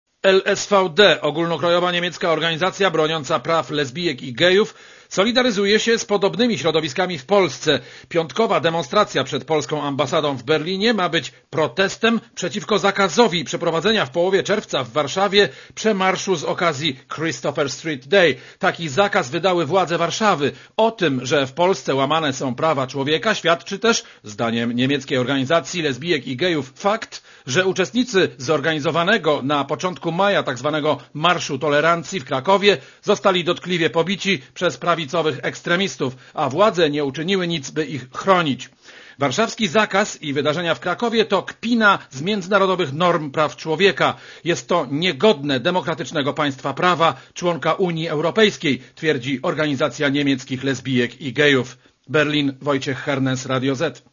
* Posłuchaj relacji berlińskiego korespondenta Radia ZET*